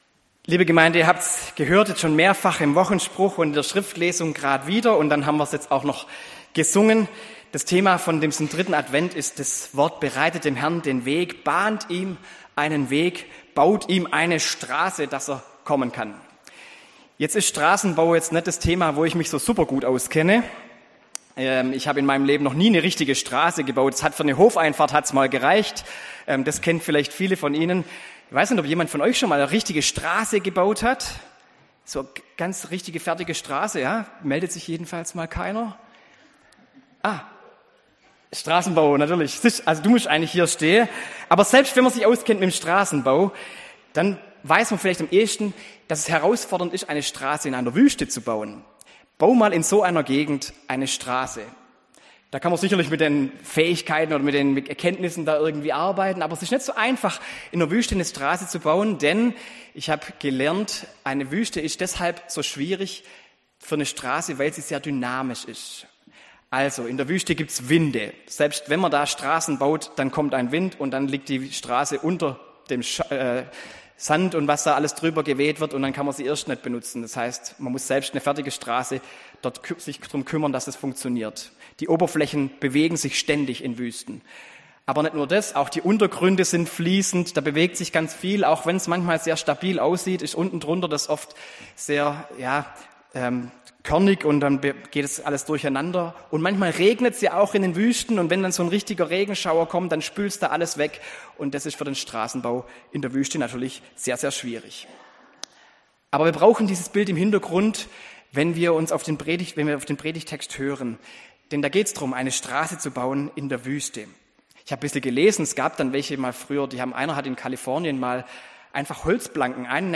Predigt
am 3. Advent